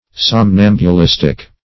Search Result for " somnambulistic" : The Collaborative International Dictionary of English v.0.48: Somnambulistic \Som*nam`bu*lis"tic\, a. Of or pertaining to a somnambulist or somnambulism; affected by somnambulism; appropriate to the state of a somnambulist.